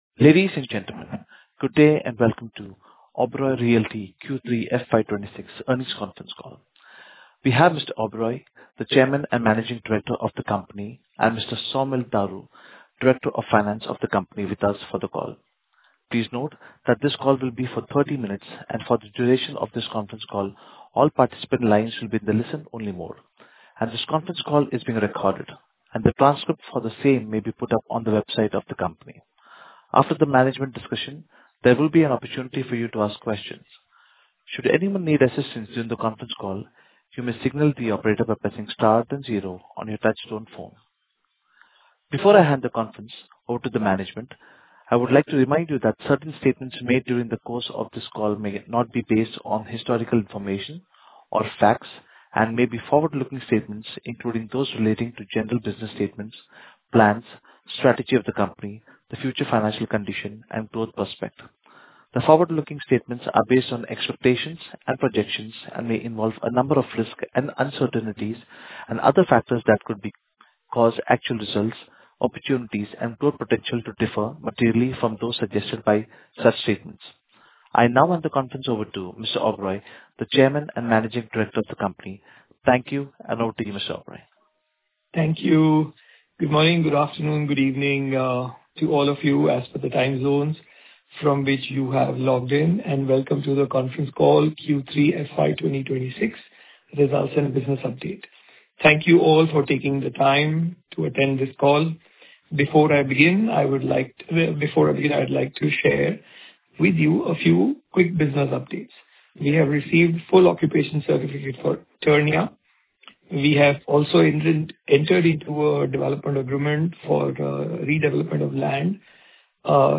Audio Recording - Q3-FY26 Earnings Conference Call.mp3